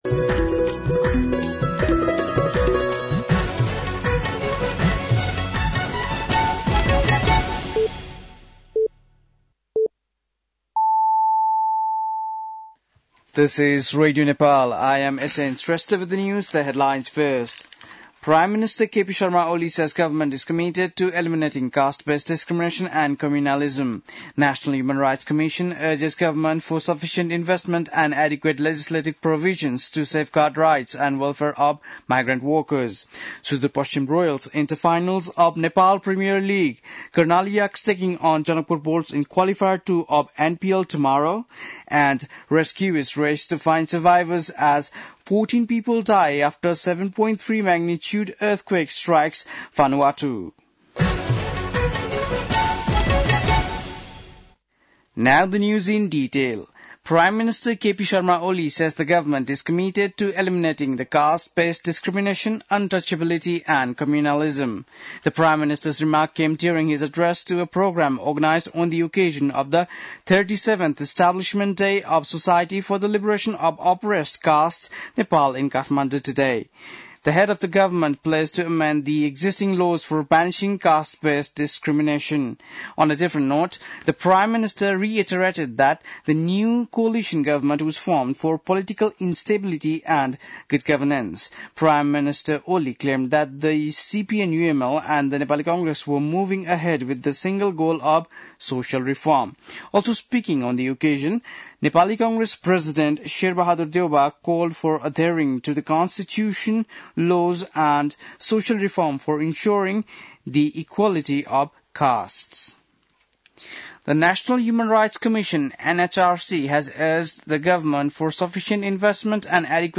बेलुकी ८ बजेको अङ्ग्रेजी समाचार : ४ पुष , २०८१
8-PM-English-NEWS-9-3.mp3